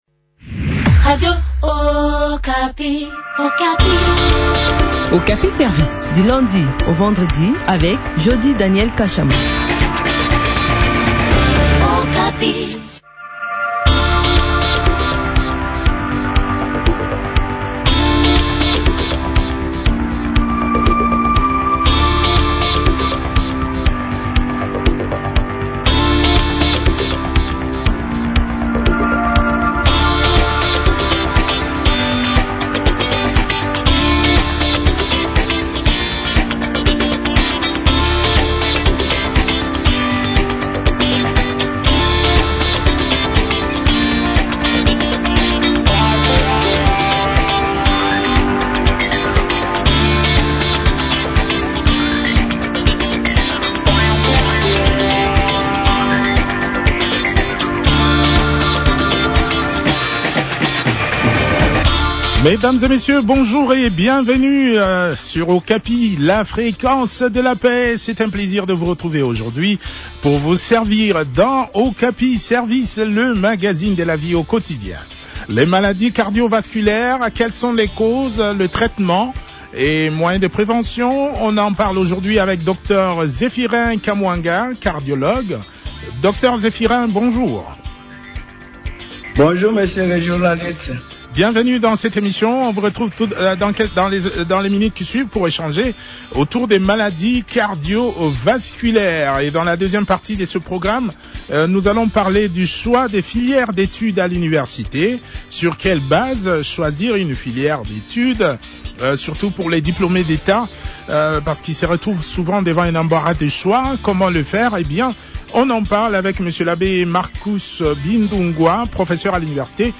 médecin spécialiste en cardiologie.